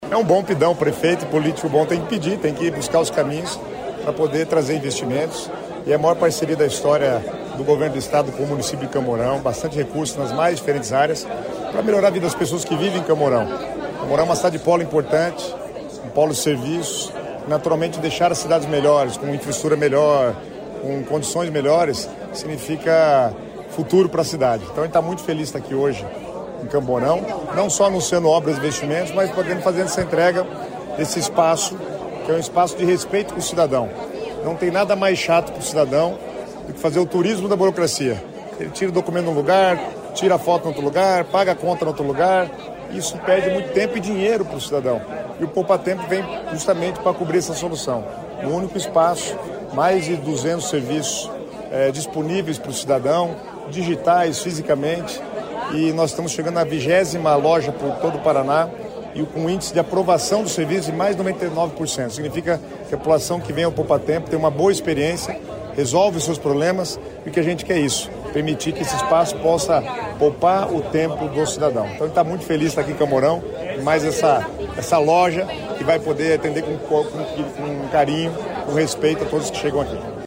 Sonora do secretário Estadual das Cidades, Guto SIlva, sobre a inauguração do Poupatempo em Campo Mourão